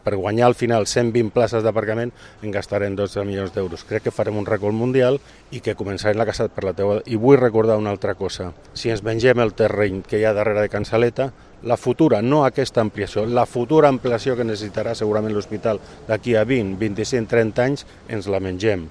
Sobre l’aparcament de Can Saleta, el regidor Sebastián Tejada, s’ha mostrat crític amb la inversió final que haurà de cobrir Gestvia, a l’espera d’obtenir crèdit.